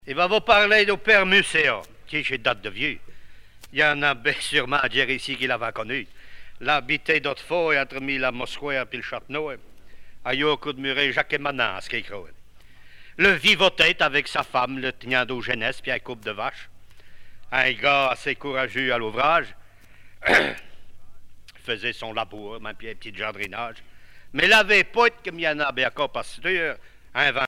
Langue Maraîchin
Genre sketch
Catégorie Récit